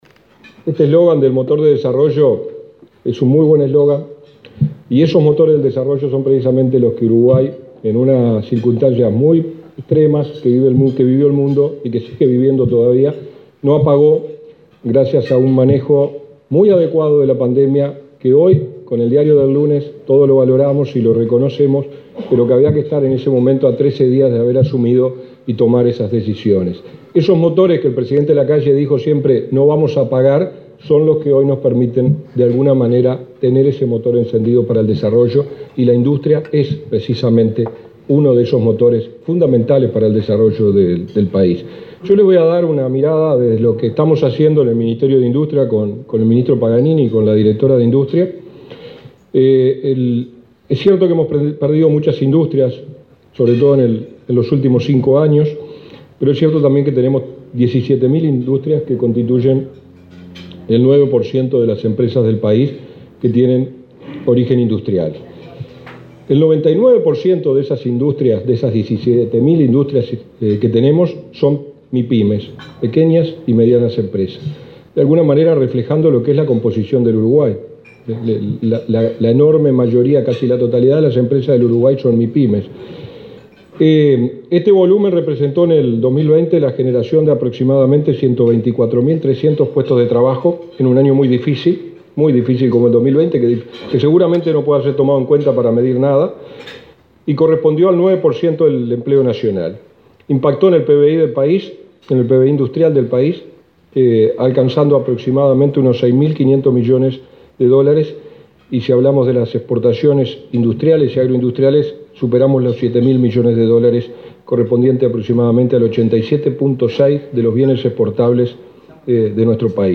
Palabras del subsecretario de Industria, Walter Verri
En el marco del Día de la Industria, el subsecretario de Industria, Walter Verri, participó este viernes 12 de la celebración realizada por la Cámara